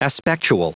Prononciation du mot : aspectual